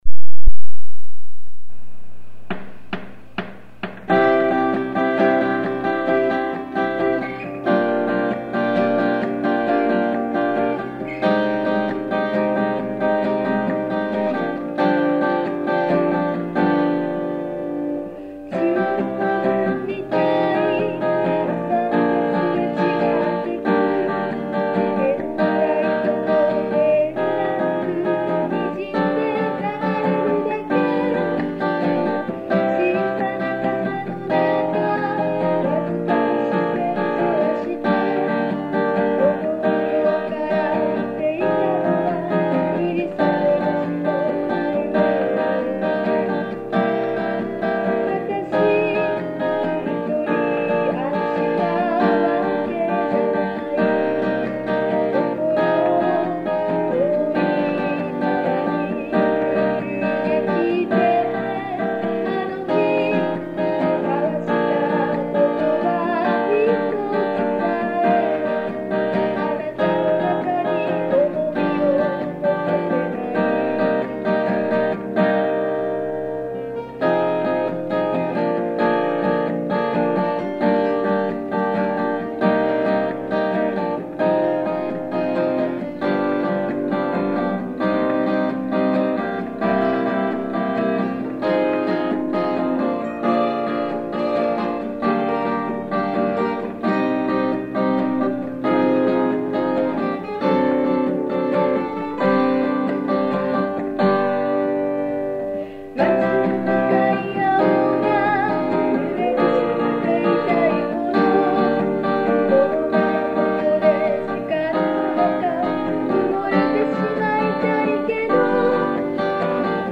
（Original　Key : b minor, by acoustic guitar）